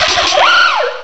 cry_not_drilbur.aif